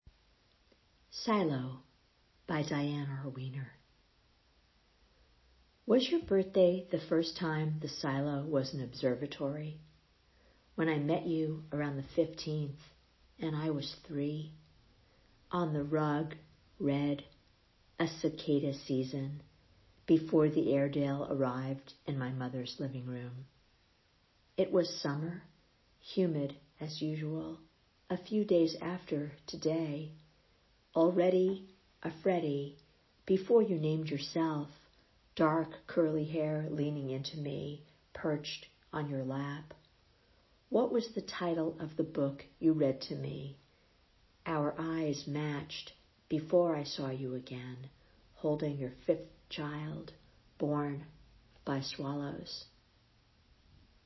(listen to the poem, read by the author)